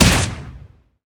magic_hit_a.ogg